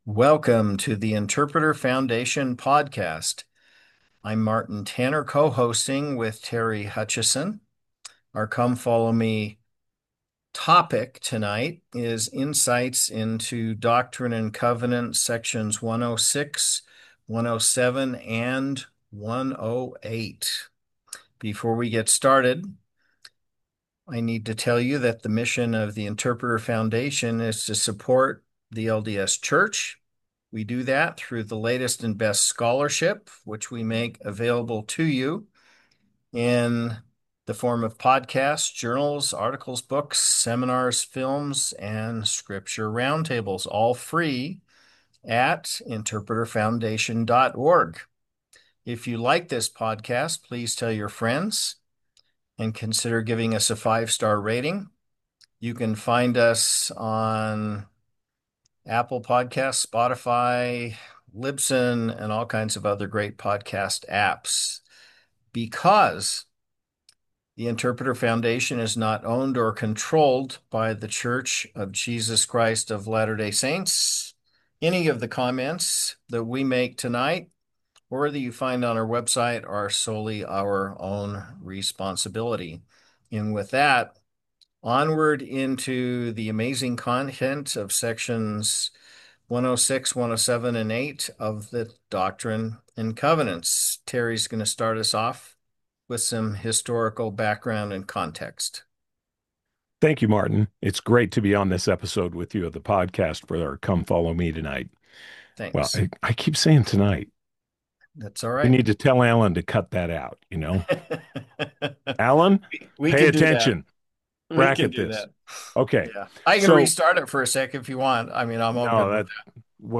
Podcast: Download The Interpreter Foundation Podcast is a weekly discussion of matters of interest to the hosts and guests.